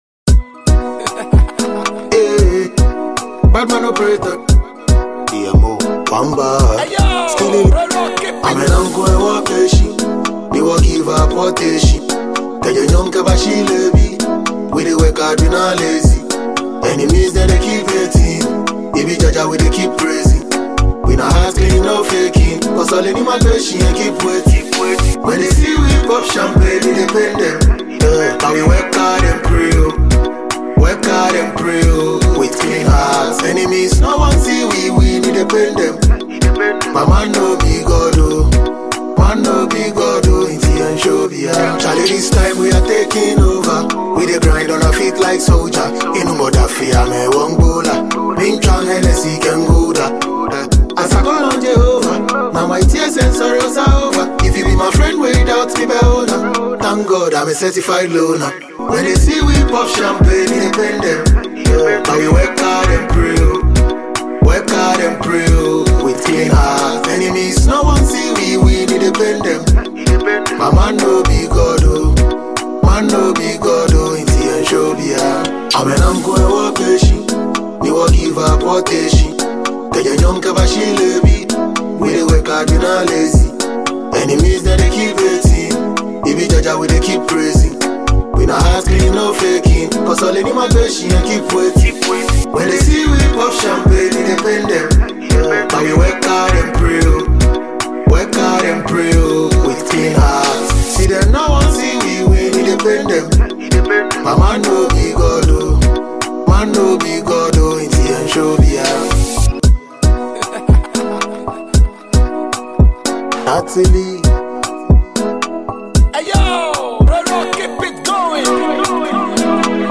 Ghanaian dancehall musician
• Genre: Dancehall